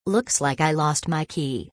（ルクスライク　アイ　ロストマイキィ）